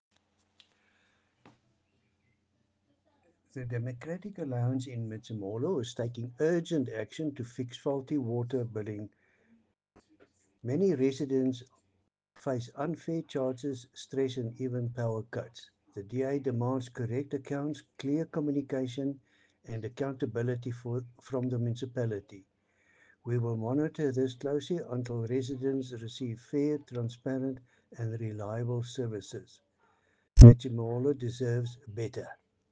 Afrikaans soundbites by Cllr Louis van Heerden and Sesotho soundbite by Jafta Mokoena MPL.